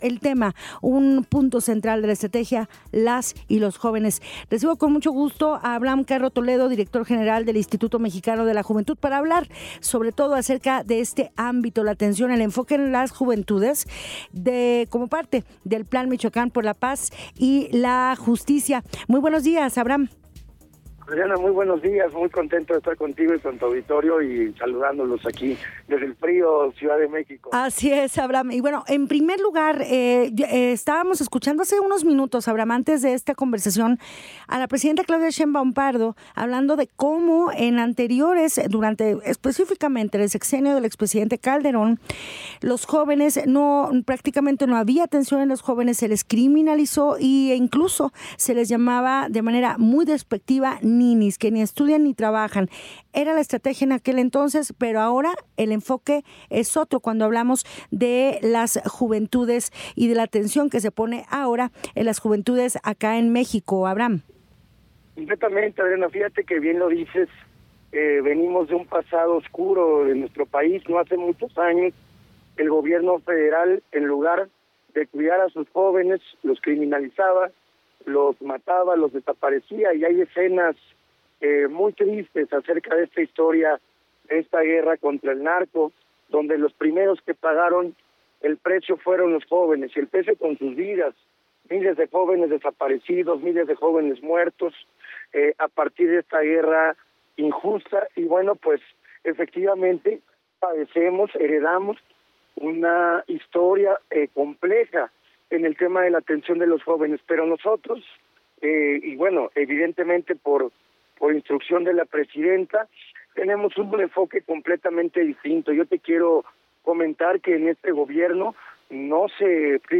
En entrevista, Abraham Carro Toledo, director general del IMJUVE, nos habla acerca de los jóvenes y su integración en el Plan de Paz.
Escucha nuestra conversación con Abraham Carro Toledo, Director General del Instituto Mexicano de la Juventud.